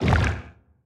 Sfx_creature_bruteshark_swim_fast_02.ogg